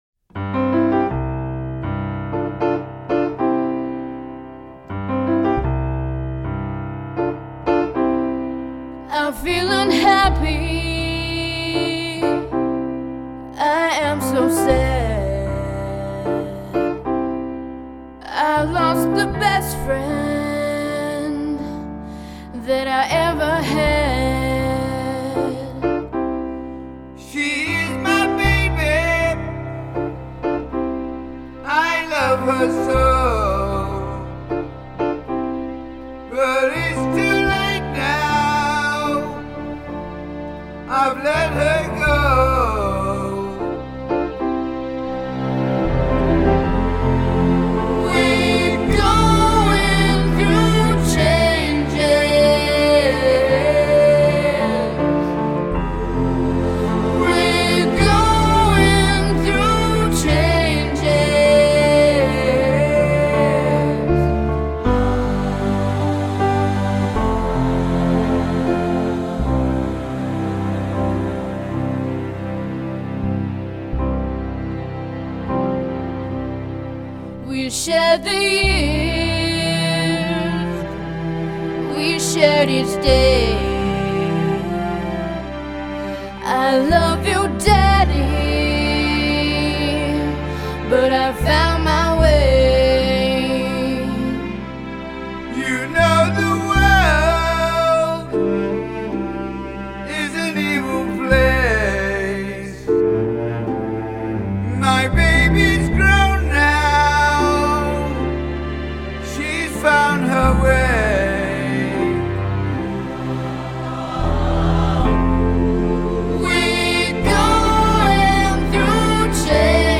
переписанная для дуэта